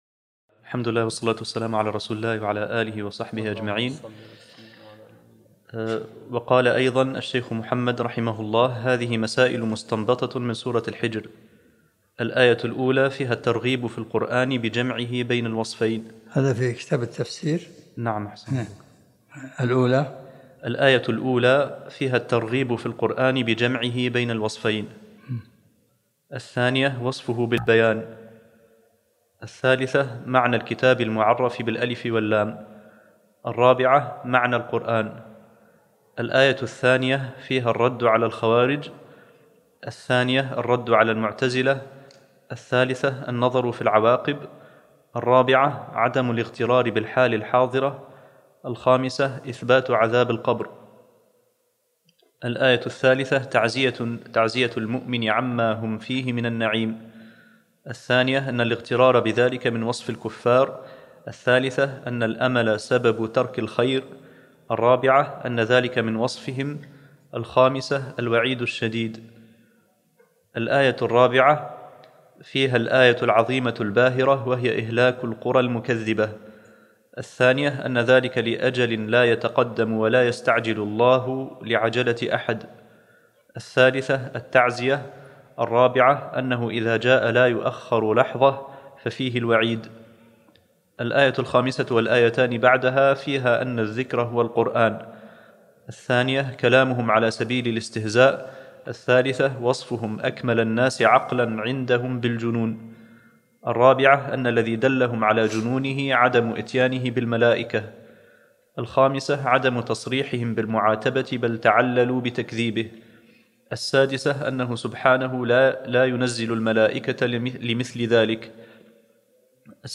الدرس السابع من سورة النحل